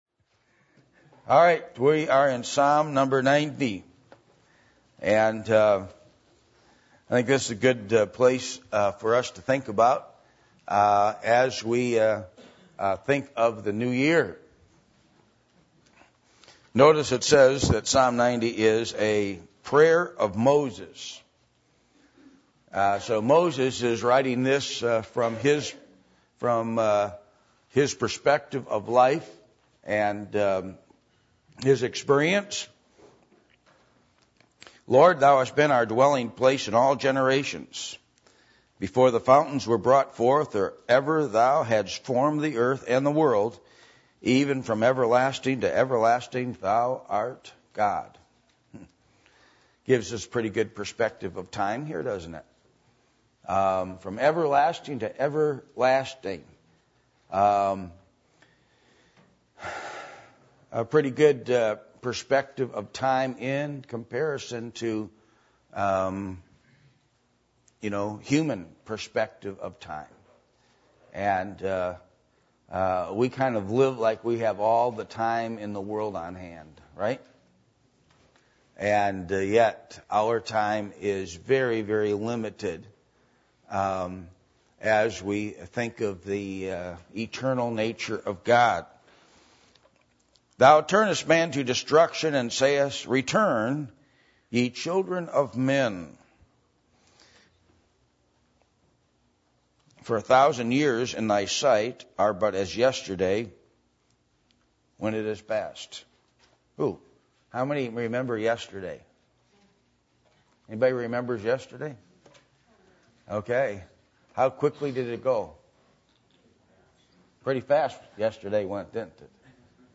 Psalm 90:1-17 Service Type: Adult Sunday School %todo_render% « Open Up The Eternal City